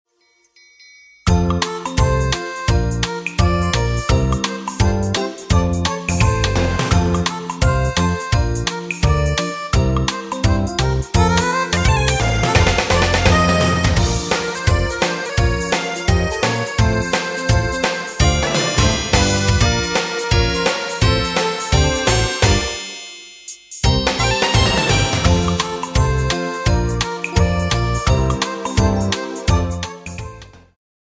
充滿動感和時代感
有伴奏音樂版本
伴奏音樂